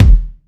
kick 26.wav